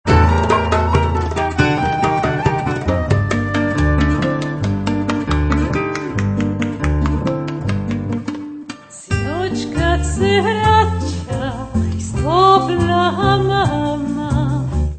chanson influences traditions